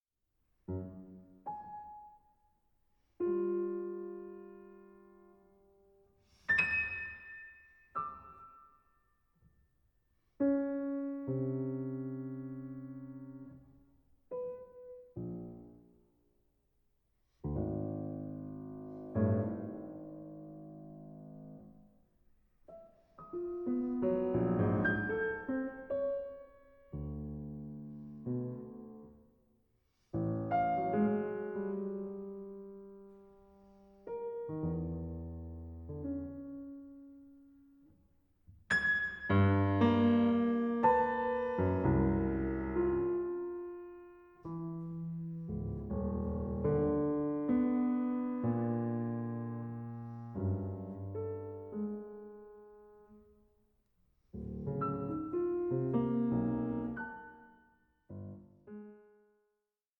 Lent 11:36